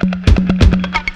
15 Foyer Guitar Shard.wav